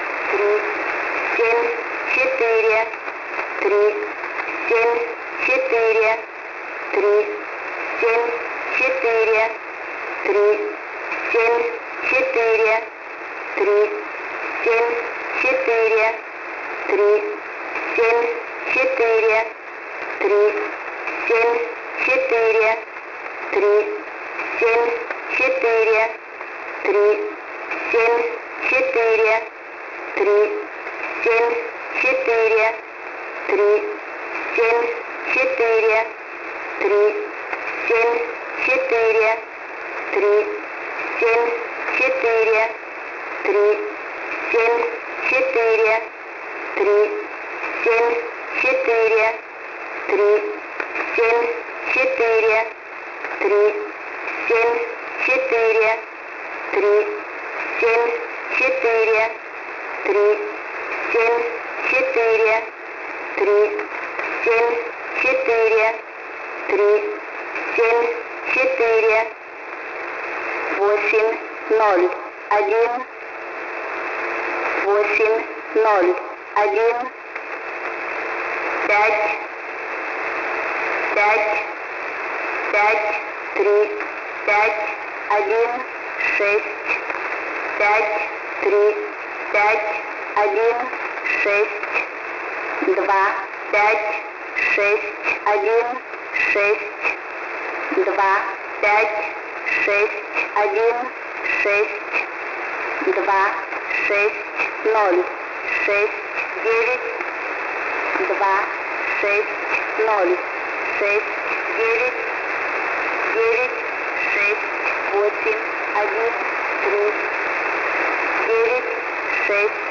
Mode: USB + Carrier Frequency: 5430 kHz